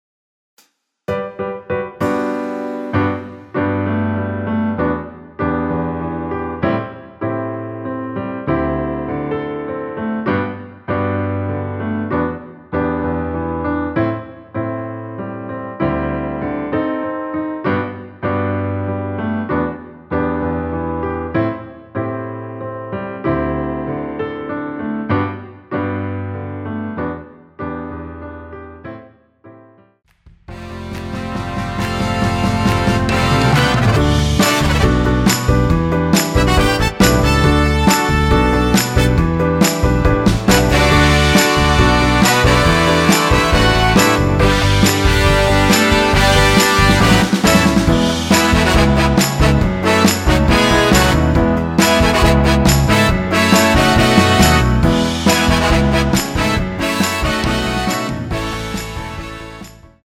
전주 없이 시작 하는 곡이라 인트로 만들어 놓았습니다.
앞부분30초, 뒷부분30초씩 편집해서 올려 드리고 있습니다.